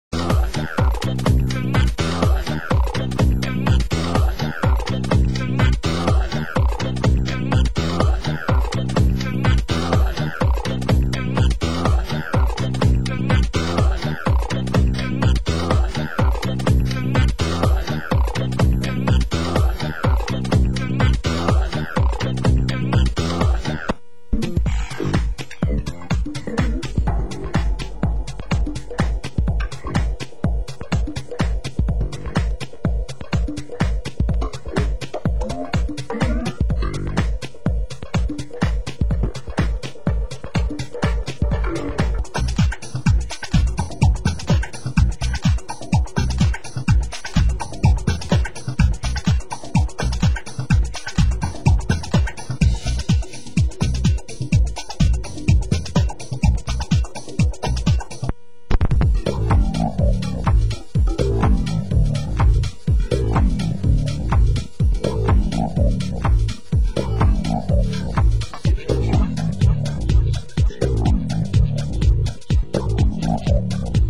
Genre Tech House